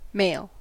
Ääntäminen
IPA : /ˈmeɪl/